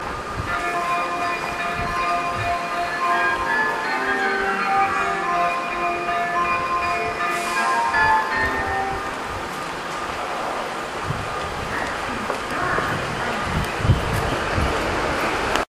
この駅の発車メロディーは１番線が「四季〜春」で２番線が「四季〜秋」が使用されています。スピーカーはNational楕円型が使用されており音質も あまりよくありません。
そのため雑音が入りやすいですね。
２番線京浜東北線
発車メロディー１ターン鳴りました。